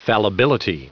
Prononciation du mot fallibility en anglais (fichier audio)
Prononciation du mot : fallibility